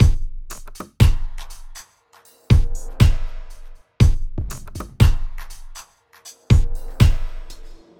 Vegas Drum Loop (120 bpm)